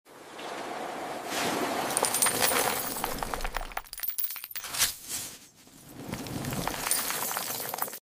Satisfying ASMR videos of Dollar's